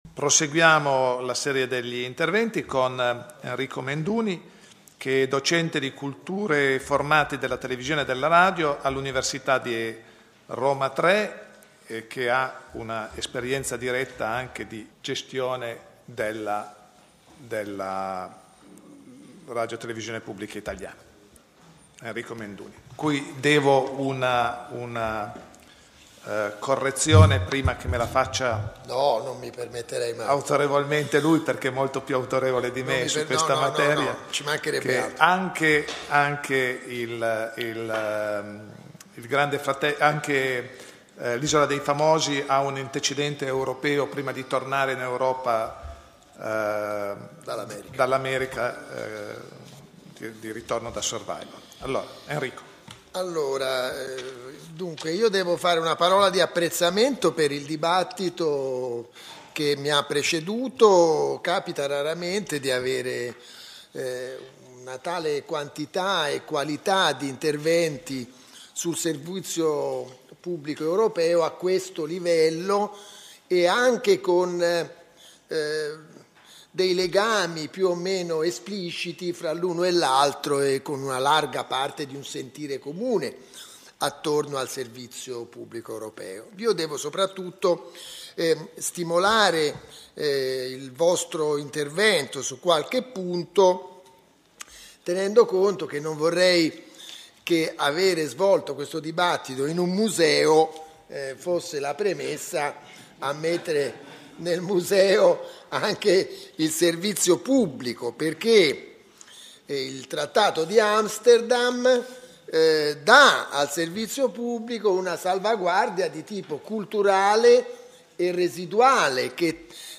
Torino Prix Italia – 24 settembre 2009
DIBATTITO